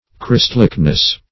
Definition of christlikeness.
Search Result for " christlikeness" : The Collaborative International Dictionary of English v.0.48: Christlike \Christ"like`\, a. Resembling Christ in character, actions, etc. -- Christ"like`ness , n. [1913 Webster]